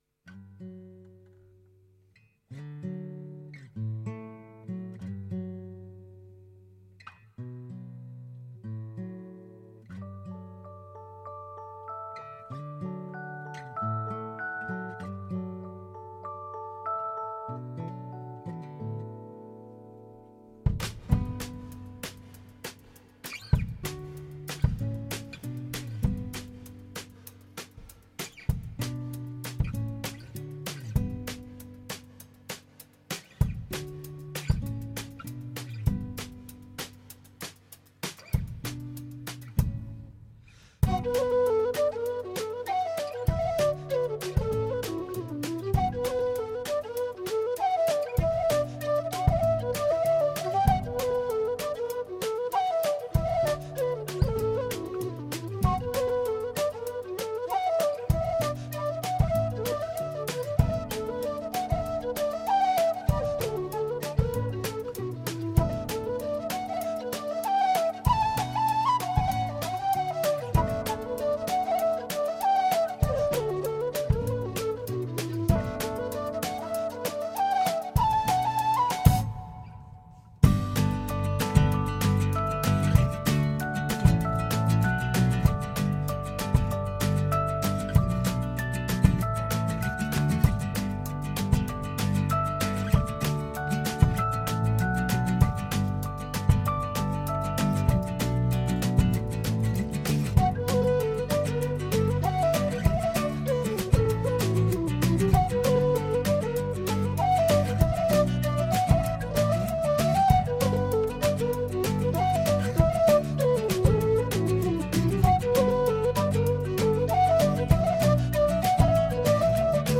Irish-american folk experimenters